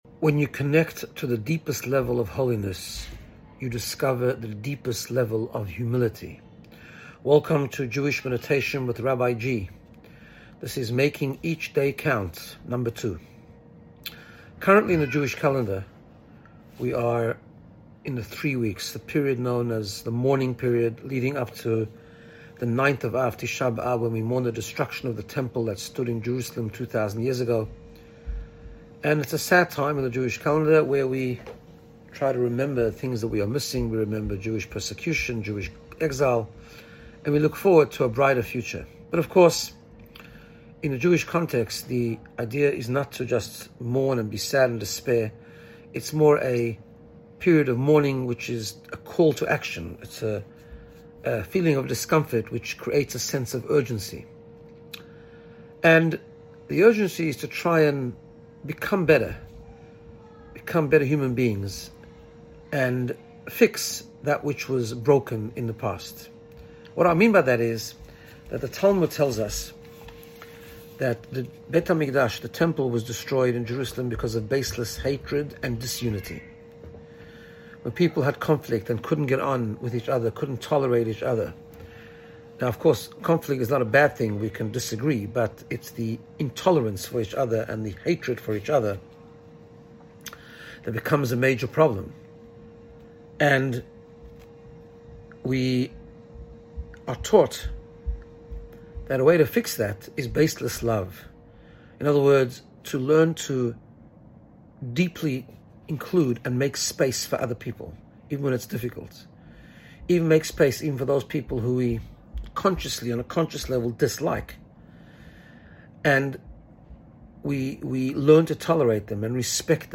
Jewish Meditation